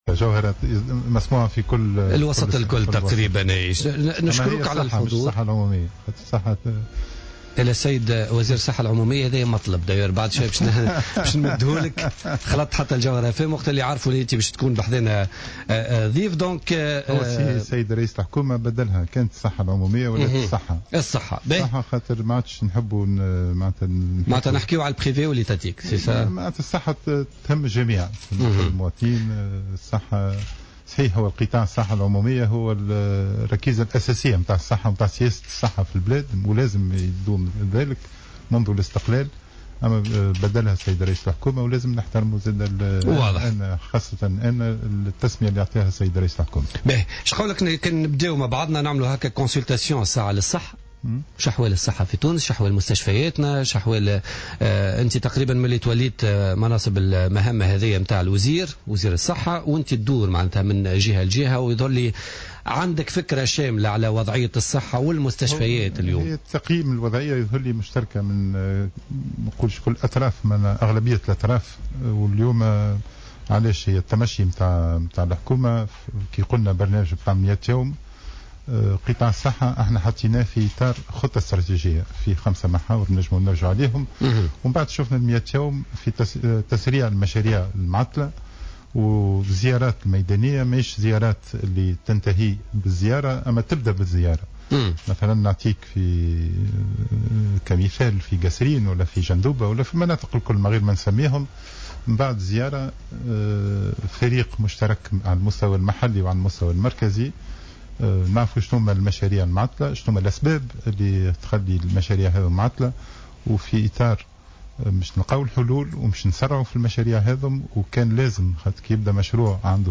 أكد وزير الصحة سعيد العايدي ضيف بوليتيكا اليوم الجمعة 10 افريل 2015 أن أولويات وزارته للمائة يوم الأولى ترتكز على تسريع نسق المشاريع المعطلة في كل الولايات مشيرا إلى أنه تم تركيز فرق عمل مشتركة على المستويين المحلي والجهوي للبحث في أسباب تعطل هذه المشاريع وإيجاد حلول عاجلة والبدء في تنفيذها .